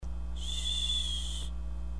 SHHHSHH
SHHHSHH - Tono movil - EFECTOS DE SONIDO
Tonos gratis para tu telefono – NUEVOS EFECTOS DE SONIDO DE AMBIENTE de SHHHSHH
ShhhShh.mp3